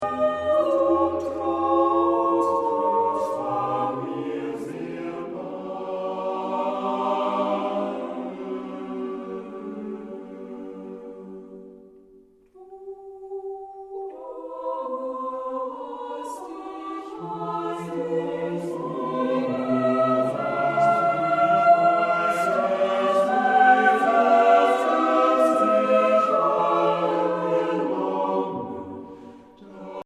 Eine Aufnahme überwiegend romantischer Chorliteratur
klangschönen und nuancenreichen Gesang